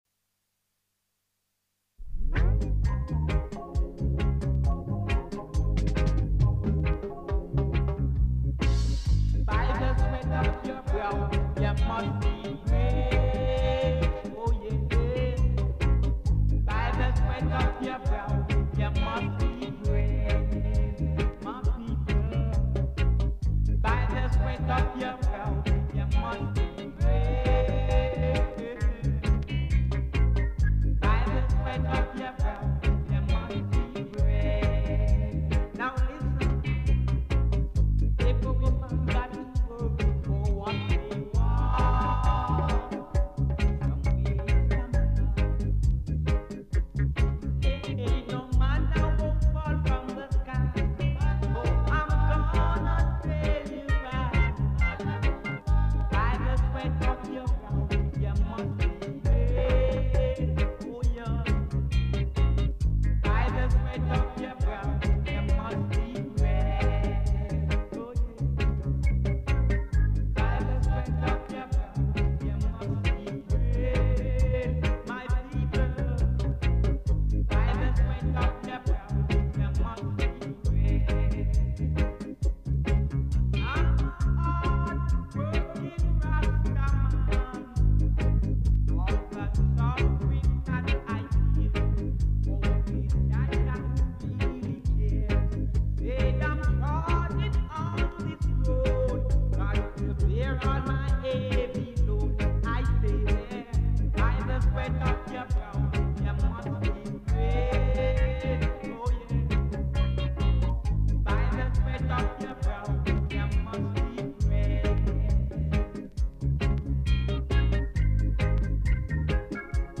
Roots Radio